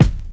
DIRTY 1.wav